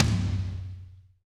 -DRY TOM 1-L.wav